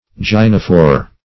Gynophore \Gyn"o*phore\ (j[i^]n"[-o]*f[=o]r), n. [Gr. gynh`